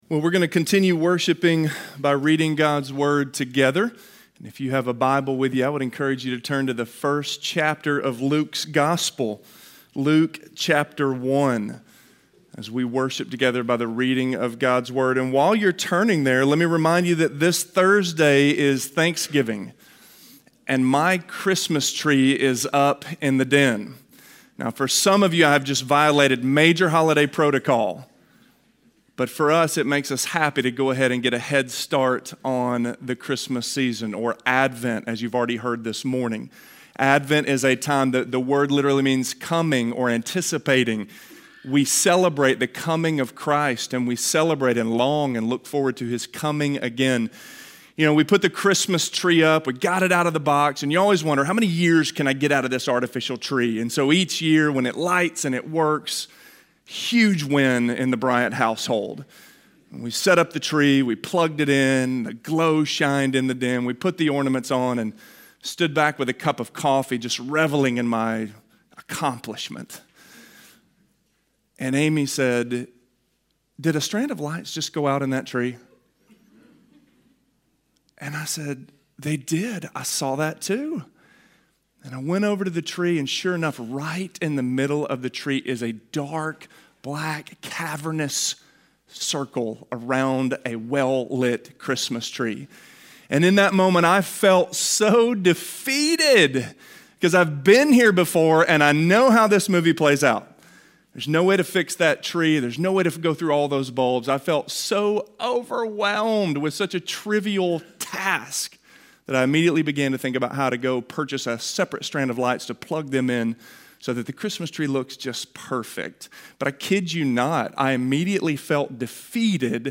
The Once and Future King - Sermon - Avenue South